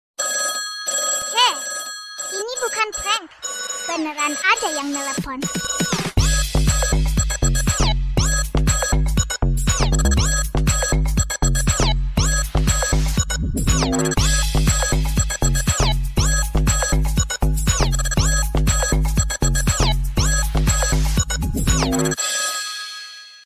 Genre: Nada dering remix
Suaranya catchy, lucu, dan bikin orang-orang penasaran.